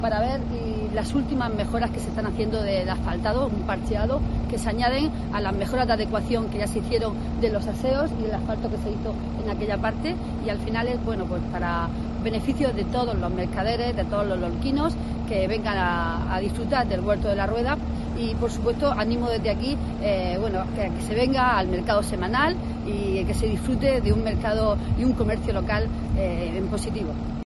Mayte Martínez, concejala de Plazas y Mercados